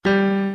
Piano Keys C Scale New